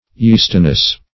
Yeastiness \Yeast"i*ness\, n. The quality or state of being yeasty, or frothy.
yeastiness.mp3